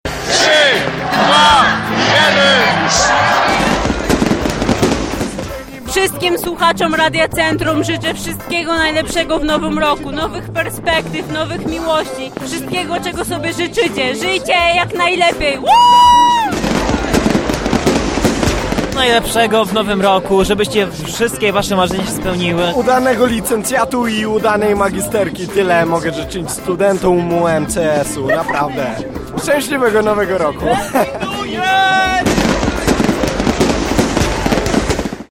Hucznie powitaliśmy nowy rok na Placu Teatralnym
Plenerowa impreza zgromadziła tysiące mieszkańców oraz przyjezdnych gości.